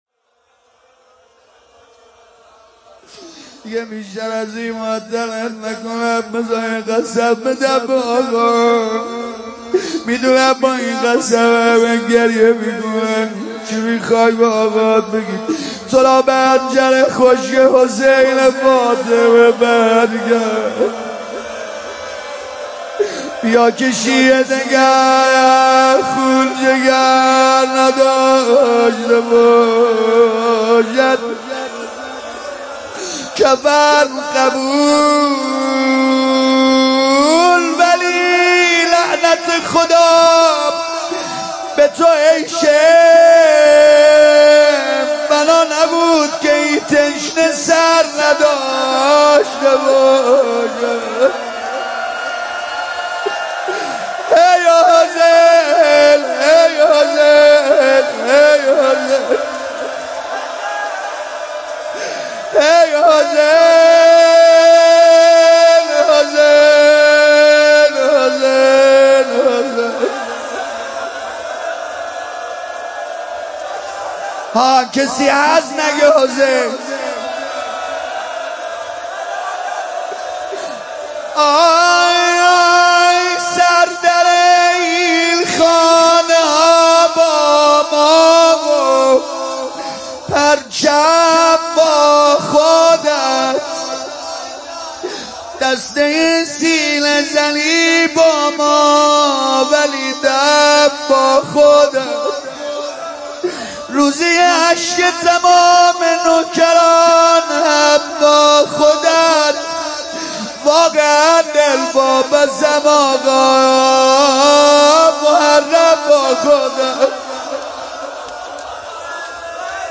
مداحی جدید
هیات فداییان حسین (ع) اصفهان